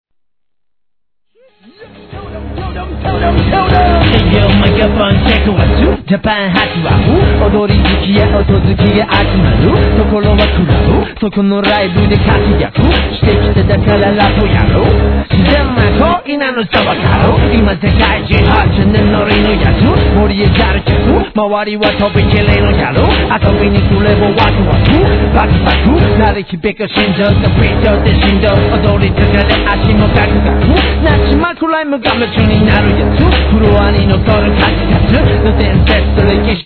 JAPANESE HIP HOP/R&B
美メロ・和モノネタ・極上日本語ラップが登場!!